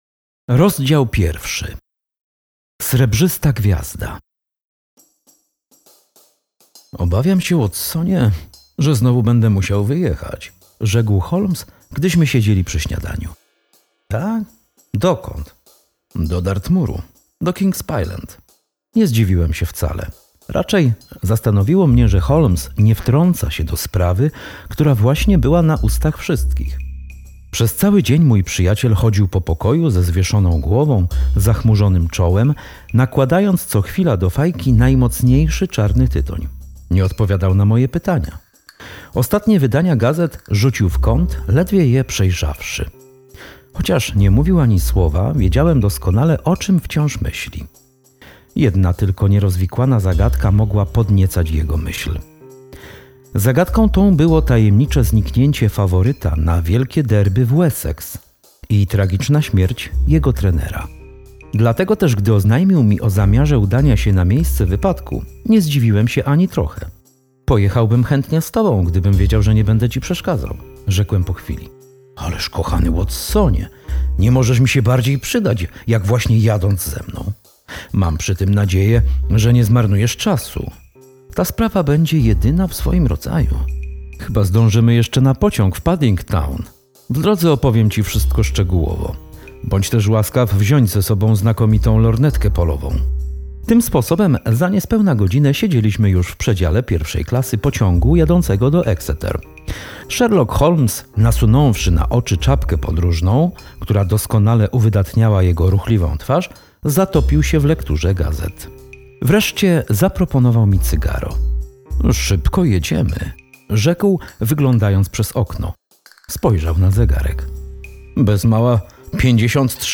Audiobook Wydawnictwa Promatek „wciąga” od pierwszego zdania również dzięki ilustracjom muzycznym.